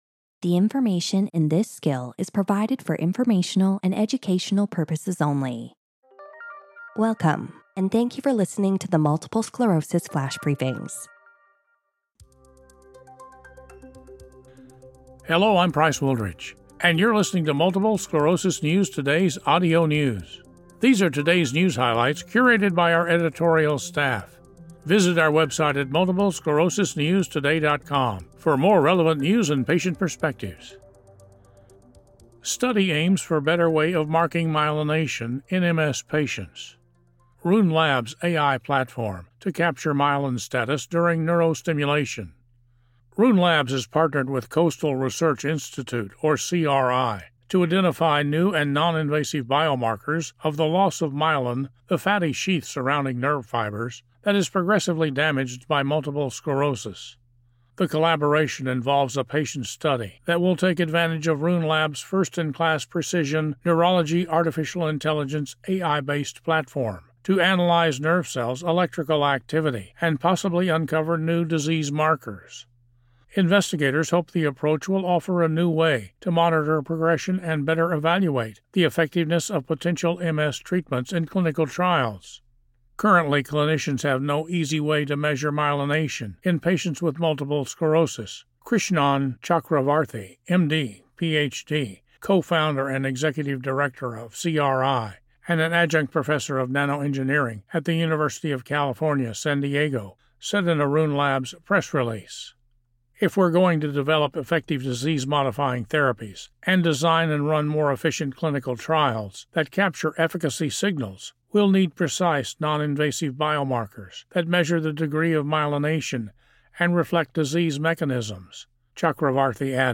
reads a news article on a study aimed at developing precise markers of myelination in people with MS.